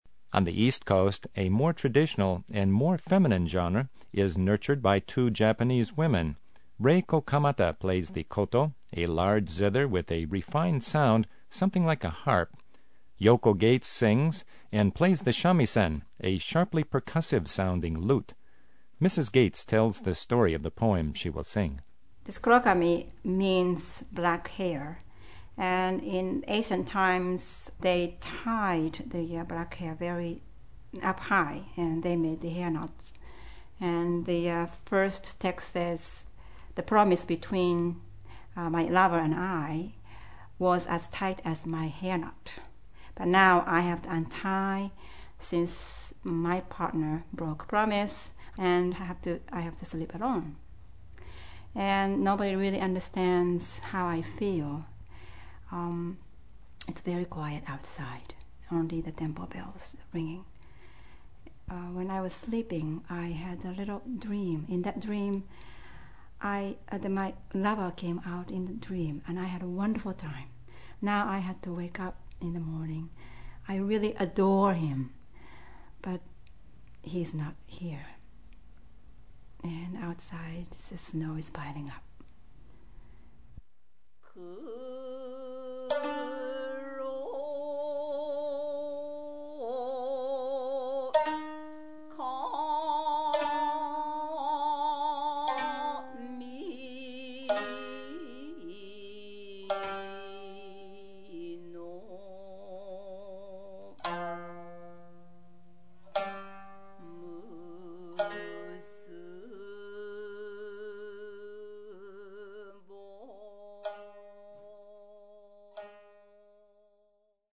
JAPANESE
Festival folk music fused with martial arts in San Francisco; koto, samisen, & voice in Rhode Island